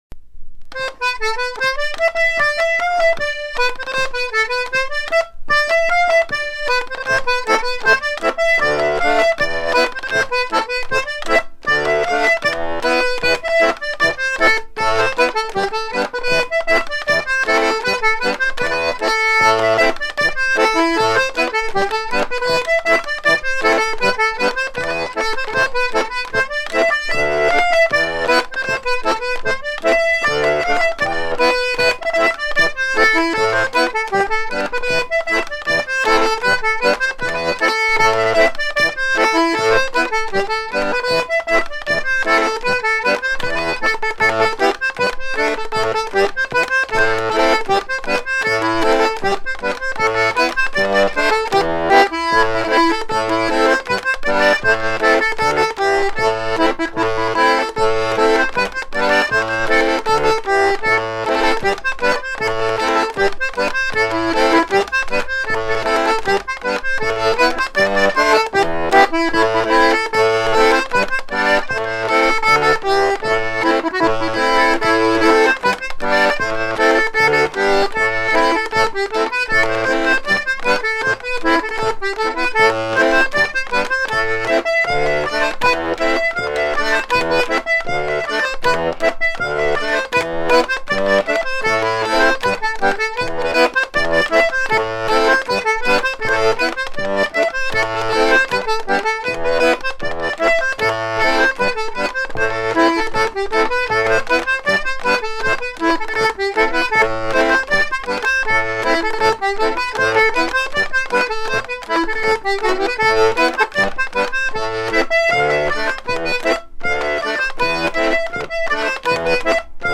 Airs vannetais servant à danser le laridé
danse : laridé, ridée